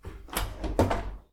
縦型洗濯機開ける
op_washer.mp3